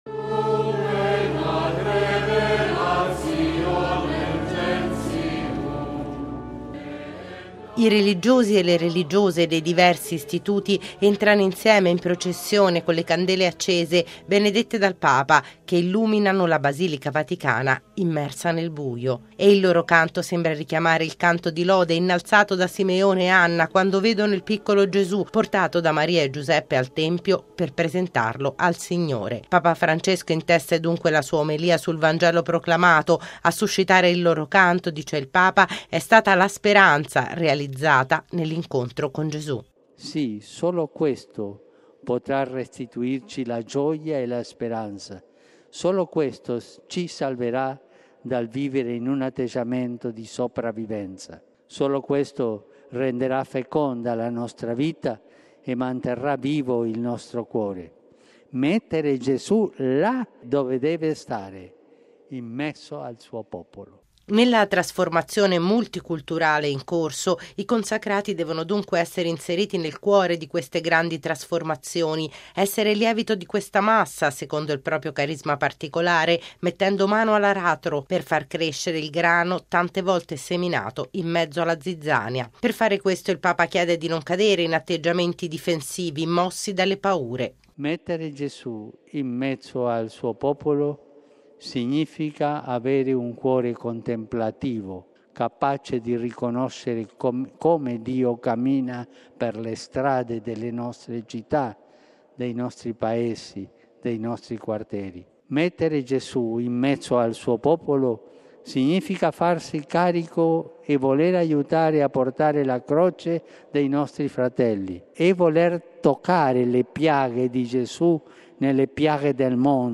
La tentazione della sopravvivenza può rendere sterile la vita consacrata. Lo ha ricordato il Papa nell’omelia della Messa celebrata, ieri pomeriggio, con i membri degli Istituti di Vita Consacrata e delle Società di Vita Apostolica nella Basilica Vaticana.
E il loro canto sembra richiamare il canto di lode innalzato da Simeone e Anna quando vedono il piccolo Gesù, portato da Maria e Giuseppe al tempio per presentarlo al Signore.